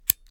fake_fire_light.2.ogg